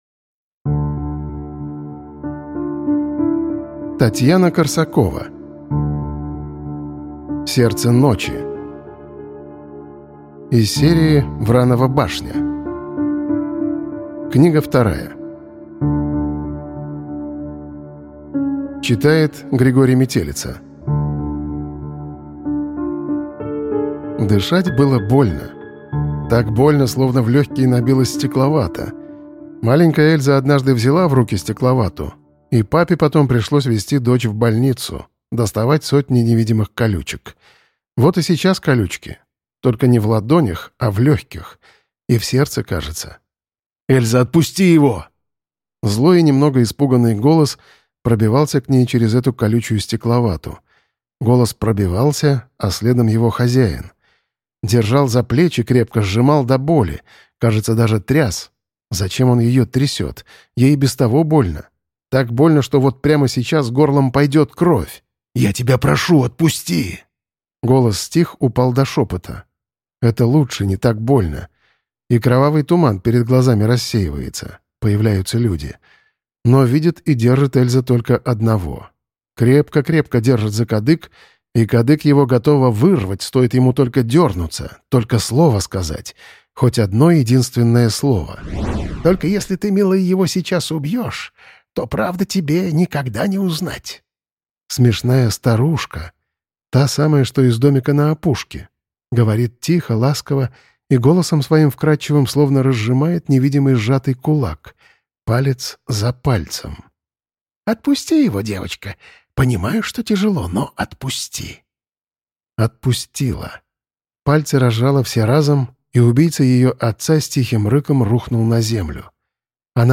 Аудиокнига Сердце ночи | Библиотека аудиокниг